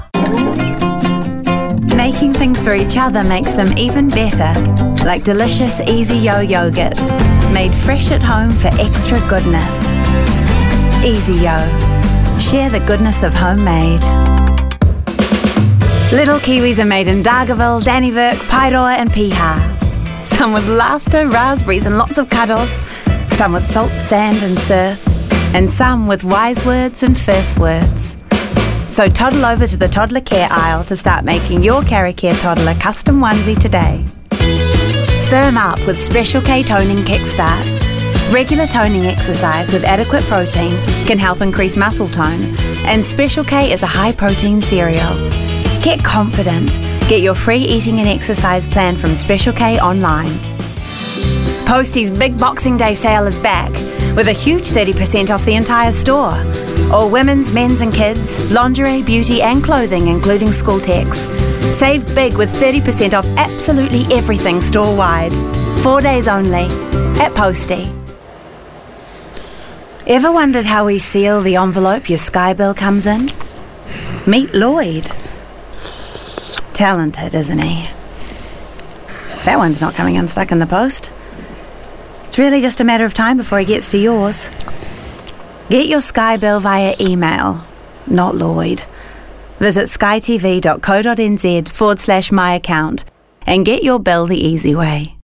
Demo
Adult
new zealand | natural
standard british | natural
standard us | natural
comedy
warm/friendly